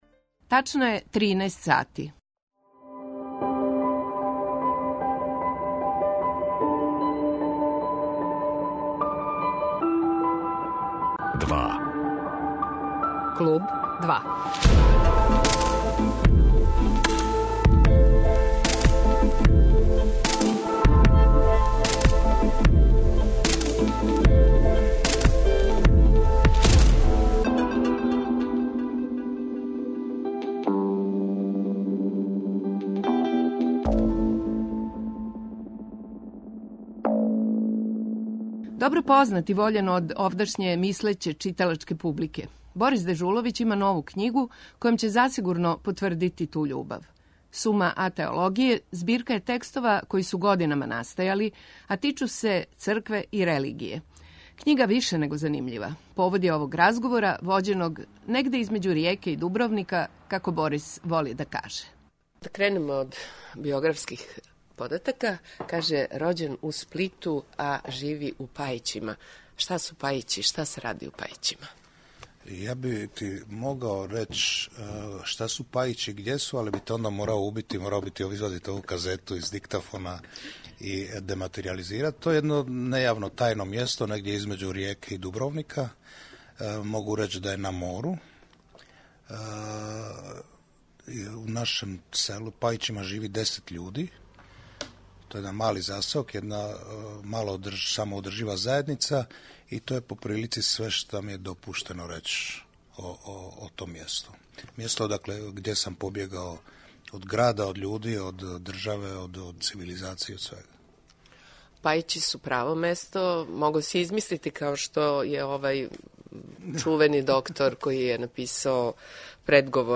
Гост Борис Дежуловић